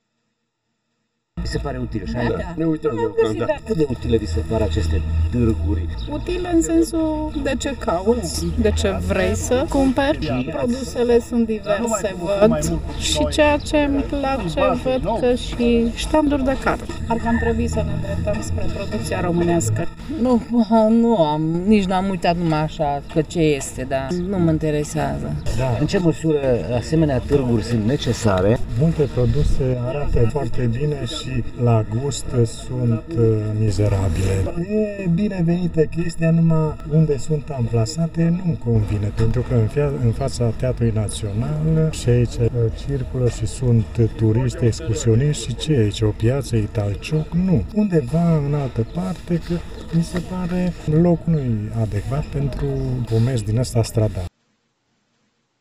Cei mai mulți târgumureșeni doar trec, se uită, și nu cumpără, dar apreciază inițiativa, spunând că preferă comerțul direct, fără intermedierea unui magazin.